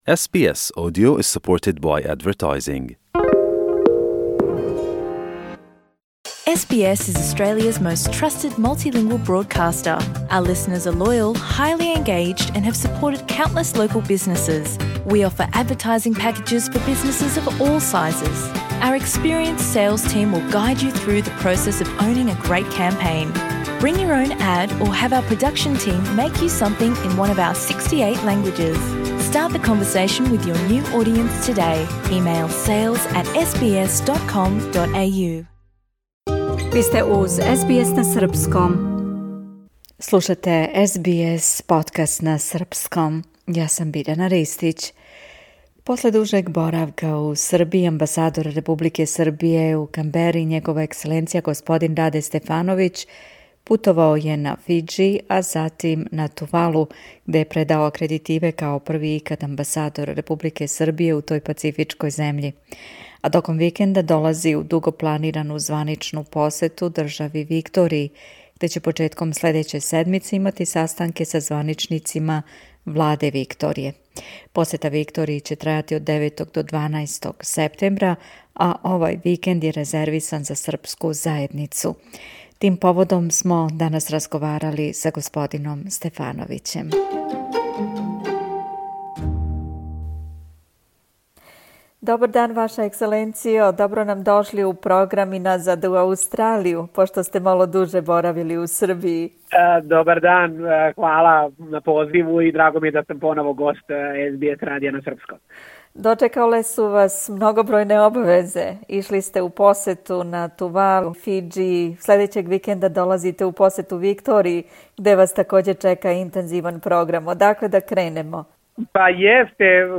Посета Викторији ће трајати од 9. до 12. септембра а овај викенд је резервисан за срспку заједницу. Тим поводом смо данас разговорали са господином Стефановићем.